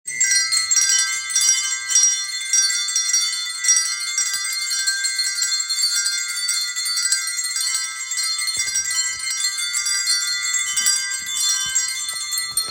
Каждый нашел и сыграл мелодию своего имени. В заключение мы устроили общий перезвон наших имен. И это оказалось так благозвучно.
kolokoljchiki.mp3